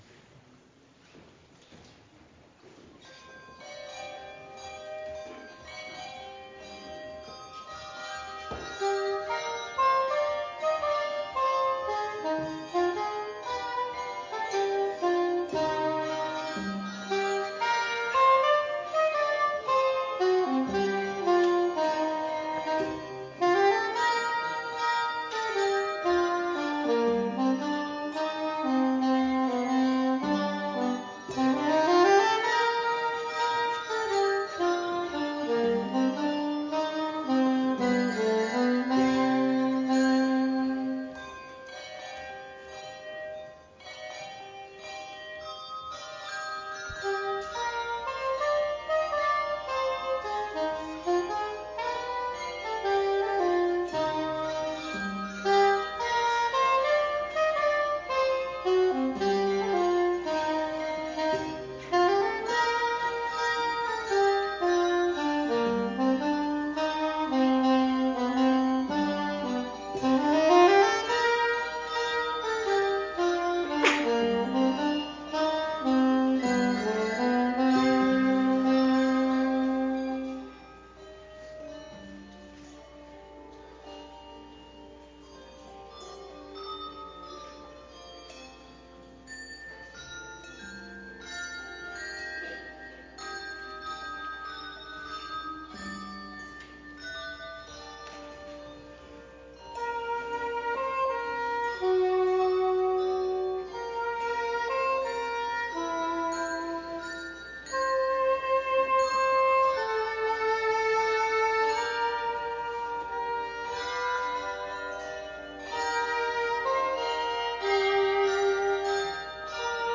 Advent Music Sunday – Services of Lessons and Carols